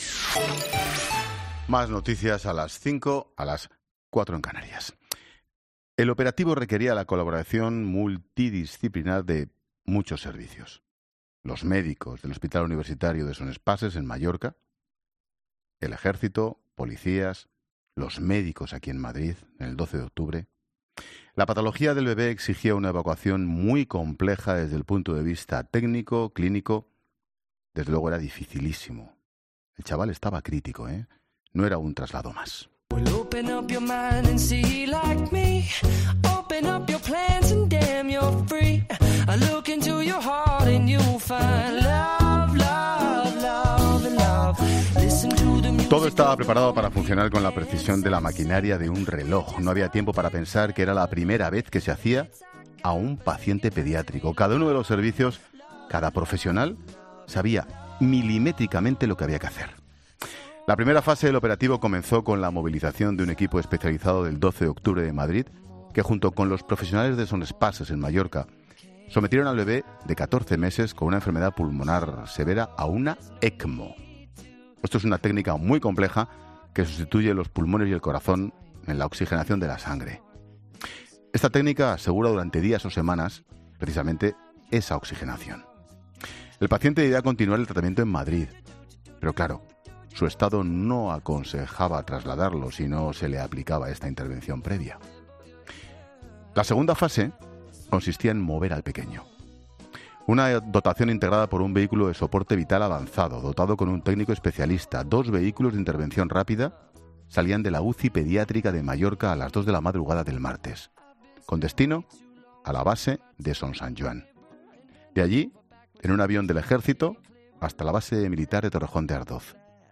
En 'La Tarde' hemos hablado